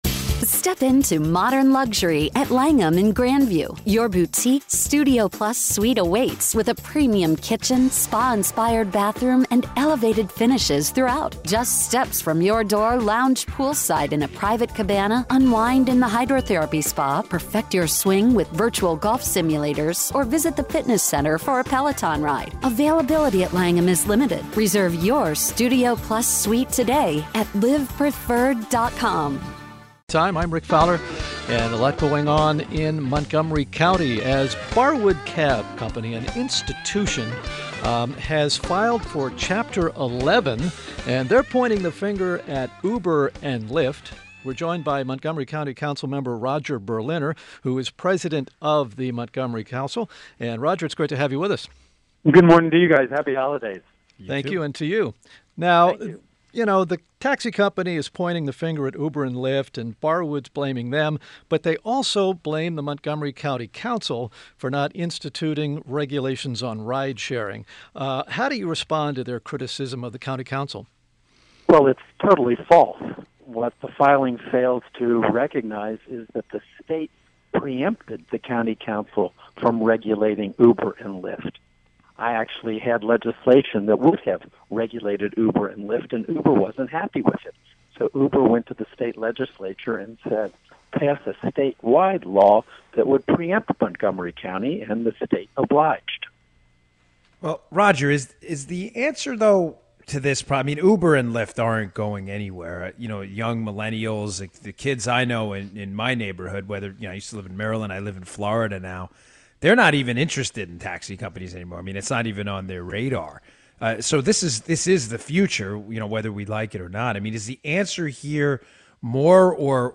INTERVIEW – MONTGOMERY COUNTY COUNCILMEMBER ROGER BERLINER – president of the Montgomery Council